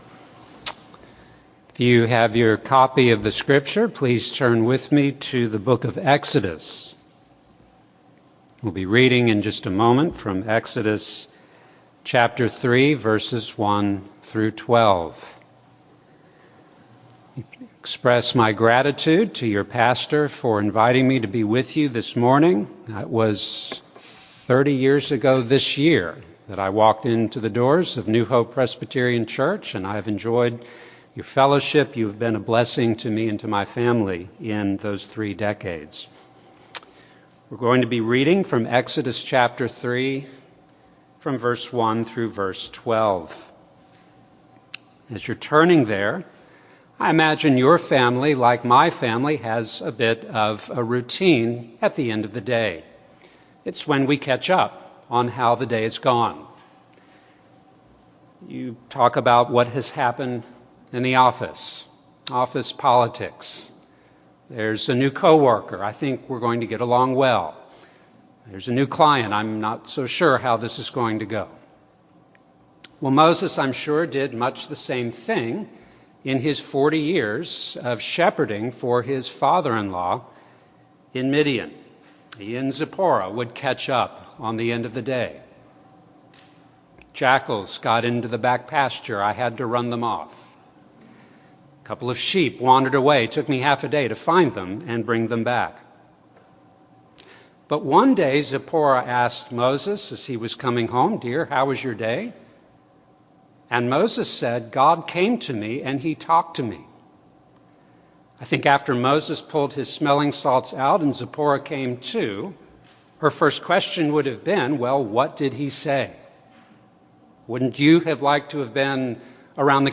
Meeting the Living God: Sermon on Exodus 3:1-12